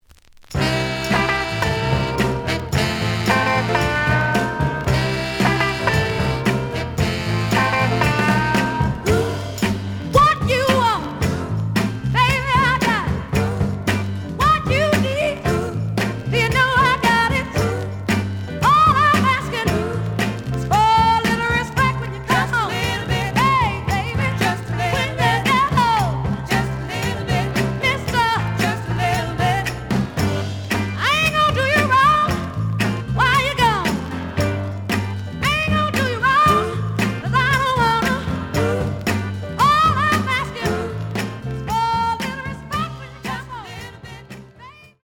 The audio sample is recorded from the actual item.
●Genre: Soul, 60's Soul
●Record Grading: VG~VG+ (傷はあるが、プレイはおおむね良好。Plays good.)